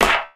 Clap (Paypal).wav